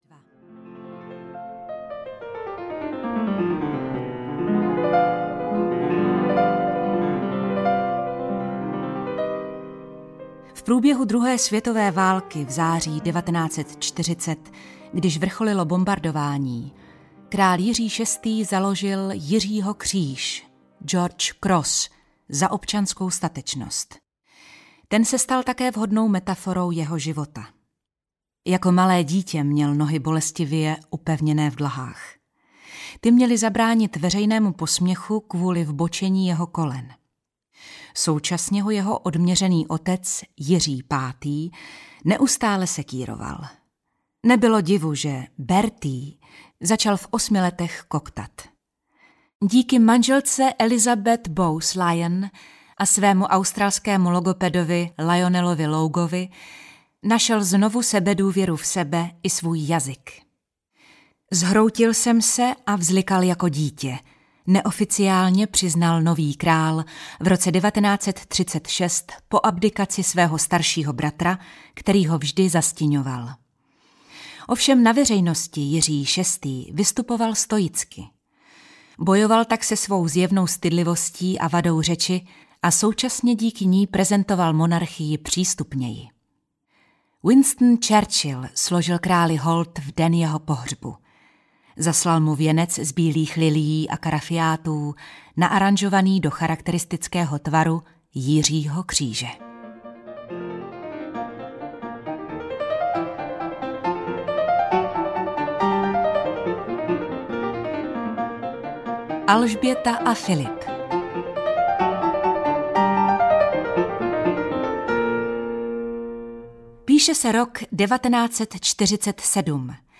Audio kniha
Ukázka z knihy
• InterpretHelena Dvořáková